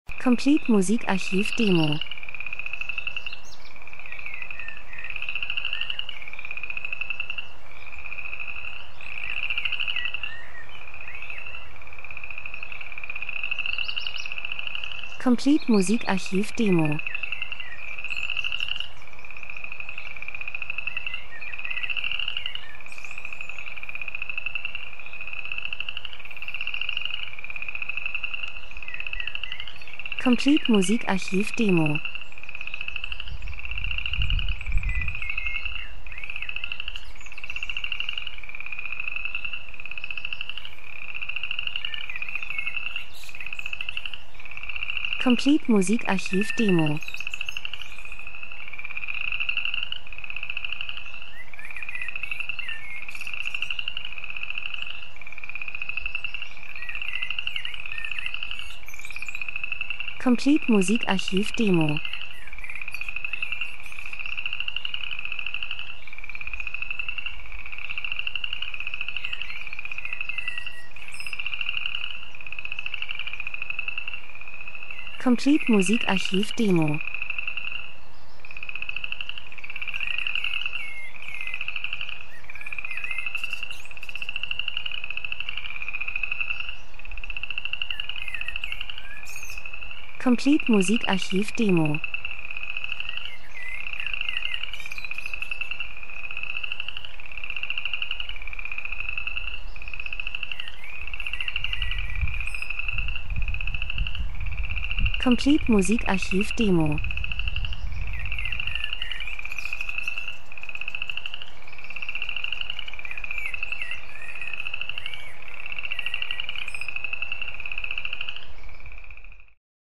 Frühling -Geräusche Soundeffekt Natur Vögel Wind Frösche 02:03